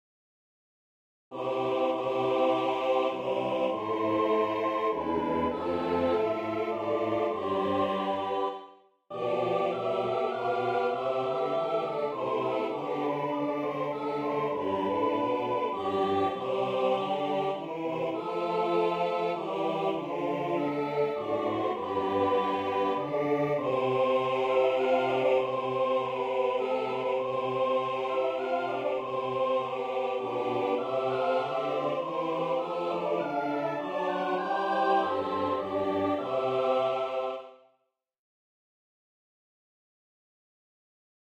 This is a choir plus congregation arrangement
Voicing/Instrumentation: SATB